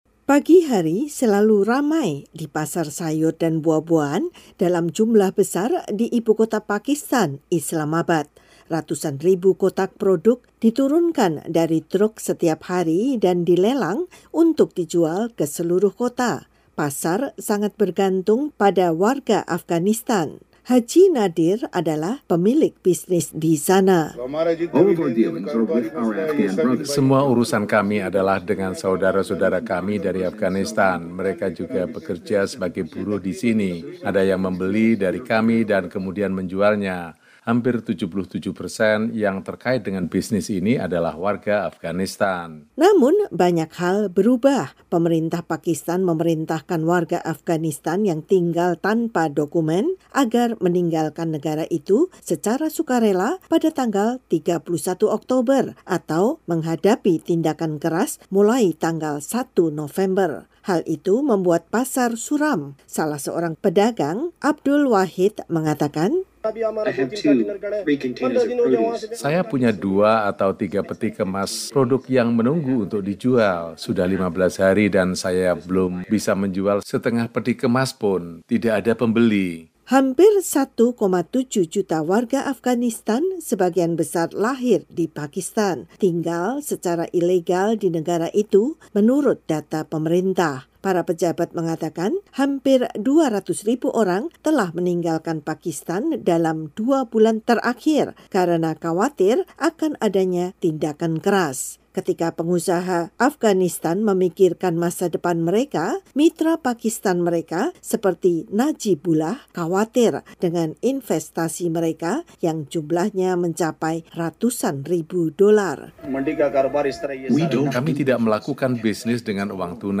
Islamabad (VOA) —
Pagi hari selalu ramai di pasar sayur dan buah-buahan dalam jumlah besar di ibu kota Pakistan, Islamabad.